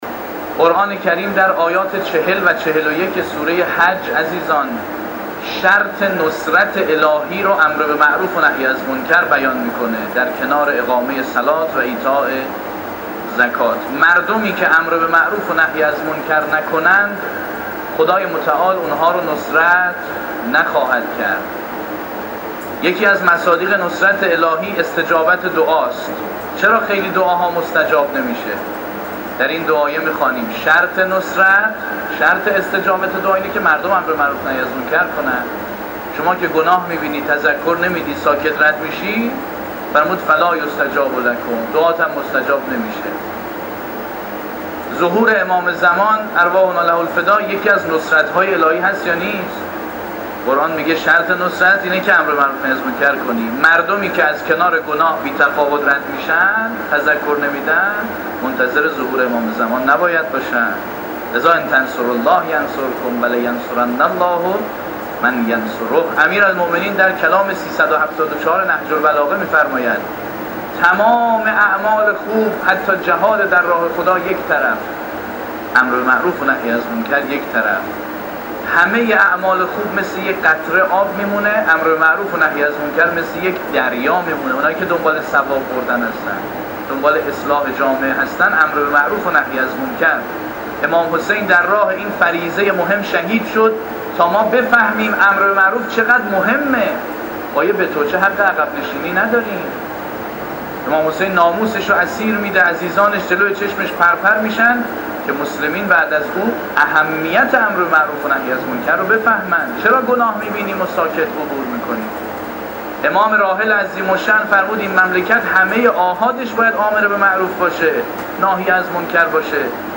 در سخنرانی پیش از خطبه نماز عبادی سیاسی جمعه تهران